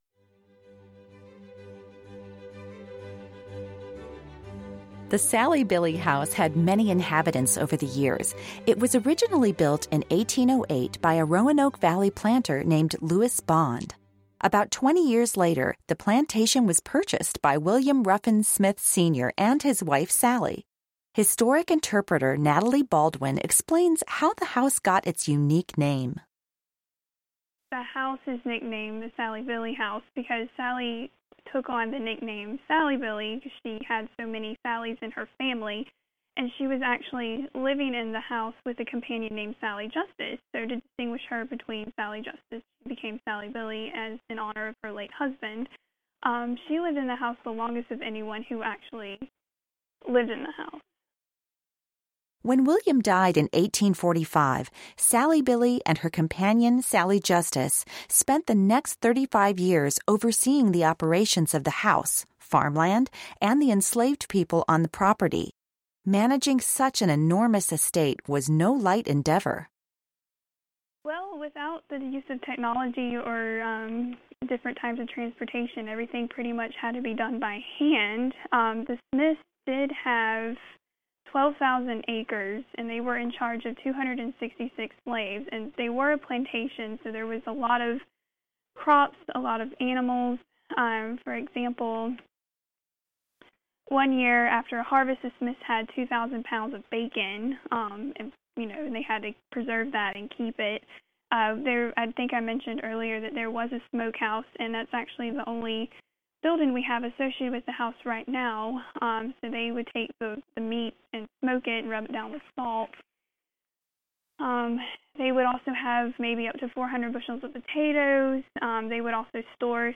Sally-Billy House - Audio Tour